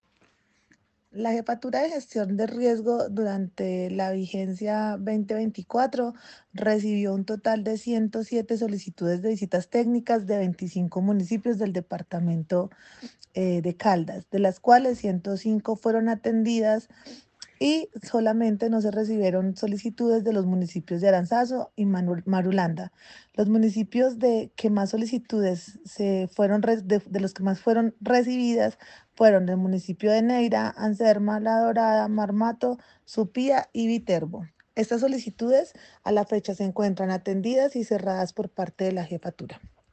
Paula Marcela Villamil Rendón, jefe de Gestión del Riesgo de Caldas.
Paula-Villamil-jefe-de-gestion-del-riesgo-de-Caldas-solicitudes-.mp3